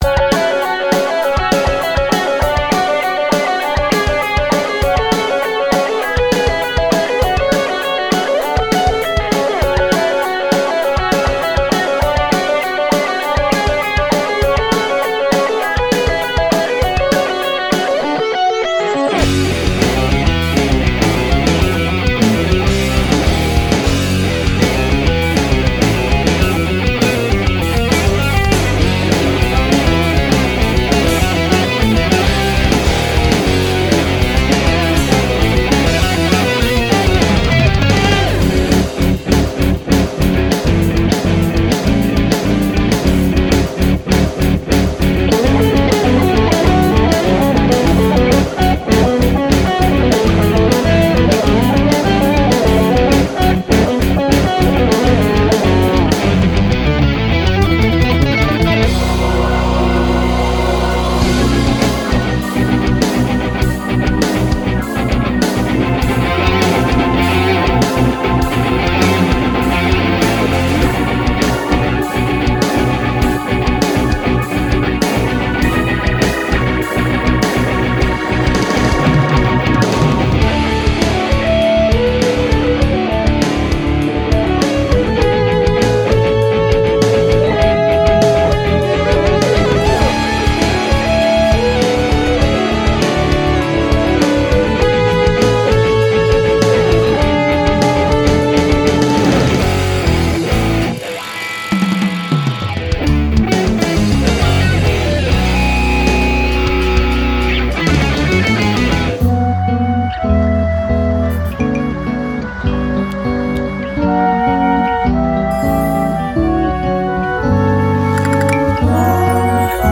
BPM100-400
Audio QualityPerfect (Low Quality)